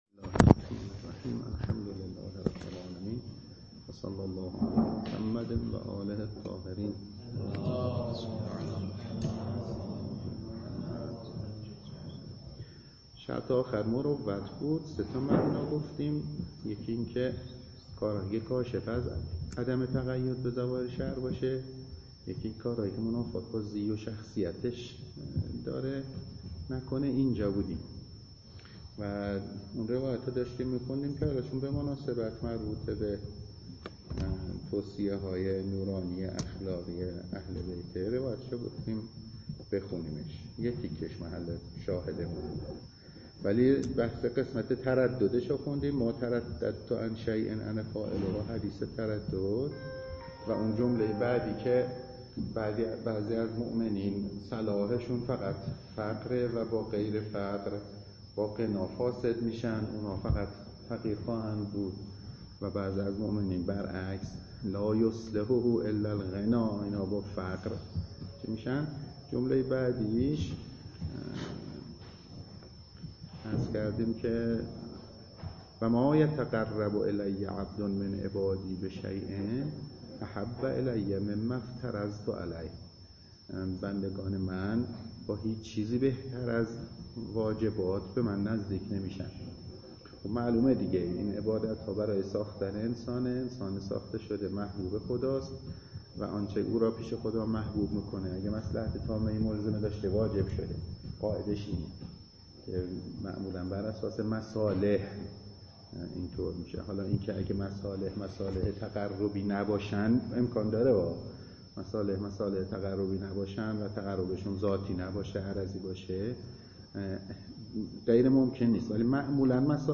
درس خارج فقه ولایت فقیه